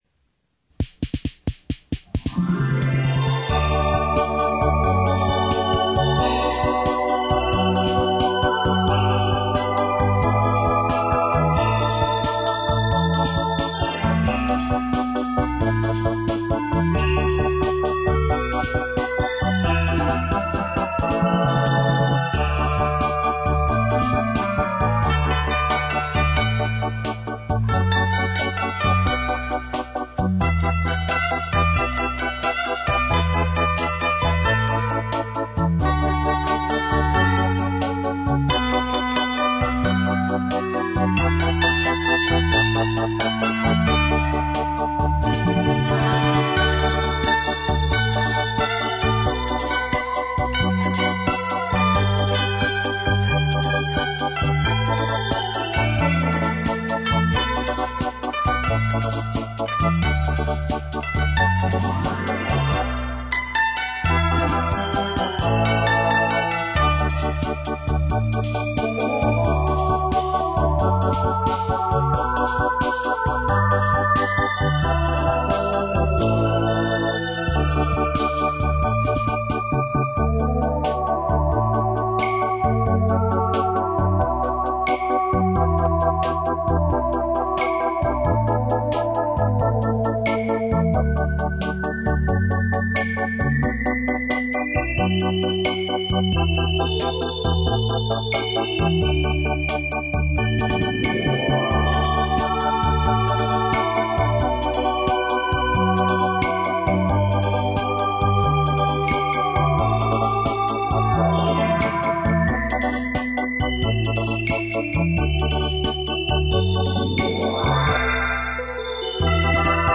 电子琴与古筝
弦音闪烁
音乐悠扬
琴弦清脆独一无二
演绎细腻动人